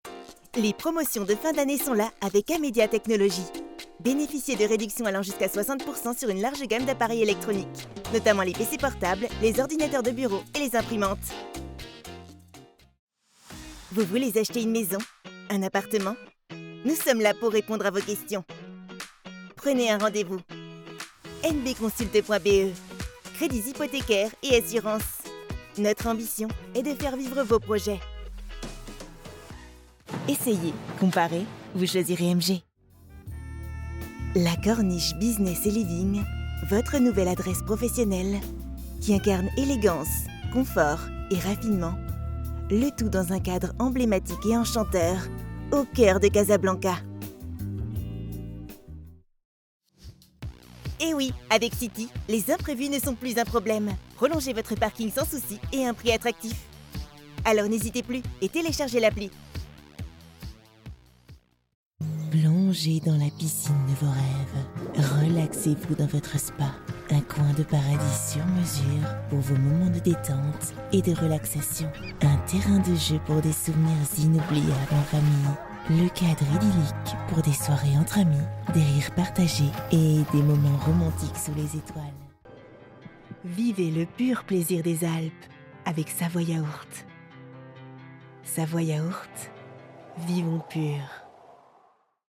Comercial, Joven, Travieso, Cálida, Suave
Comercial
She has a medium voice.